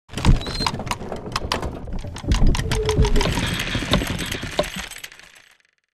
Large-wooden-mechanism-heavy-gear.mp3